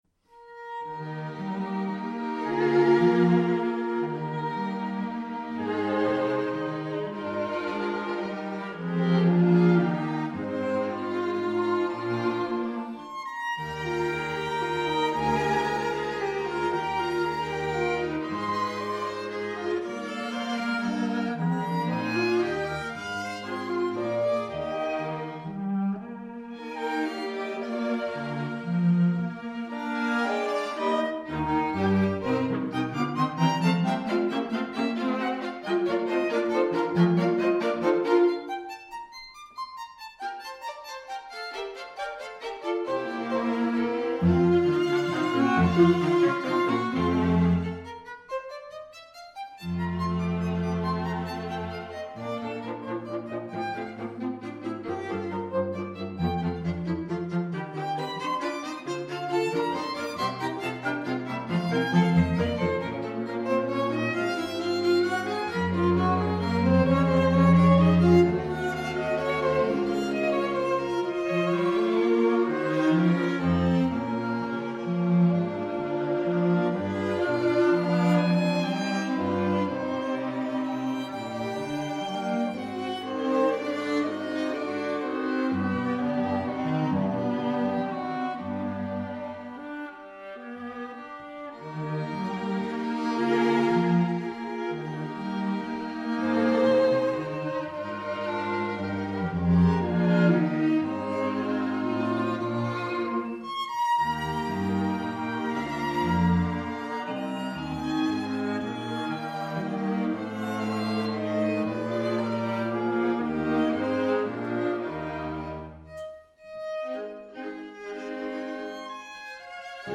For 2 Violins, 2 Violas and Violoncello